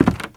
STEPS Wood, Creaky, Walk 08.wav